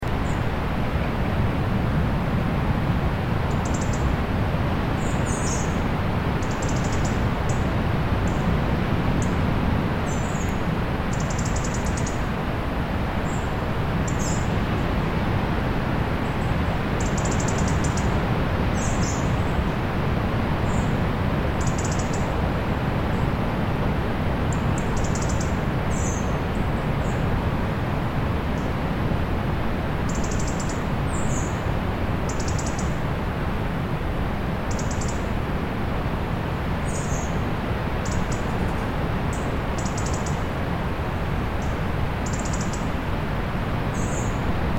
دانلود آهنگ دریا 13 از افکت صوتی طبیعت و محیط
دانلود صدای دریا 13 از ساعد نیوز با لینک مستقیم و کیفیت بالا
جلوه های صوتی